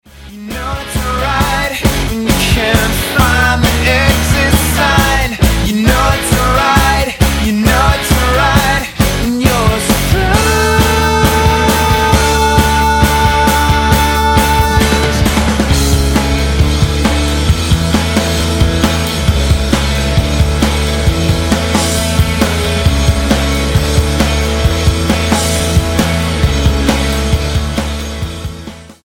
STYLE: Roots/Acoustic